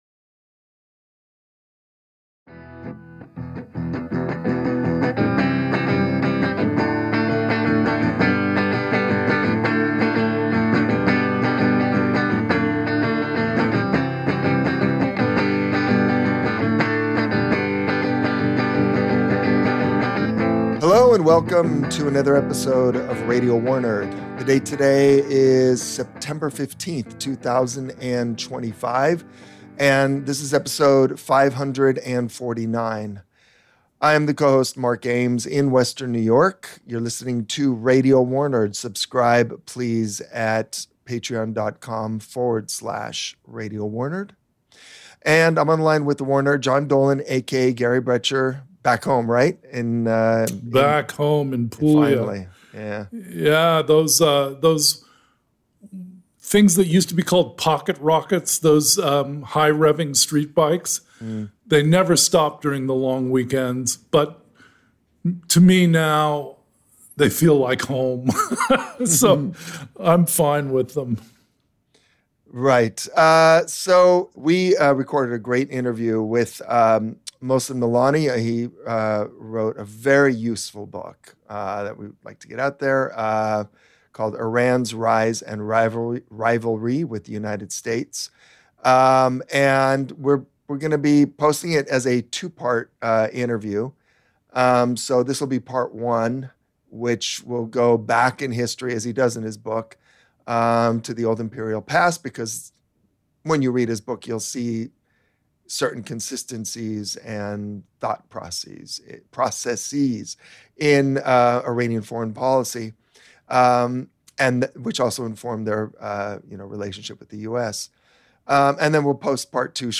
In part 1 of our 2-part interview, we begin at the beginning with the Achaemenid Empire, and take it up through Iran's 1979 Revolution and the creation of Hezbollah in Lebanon.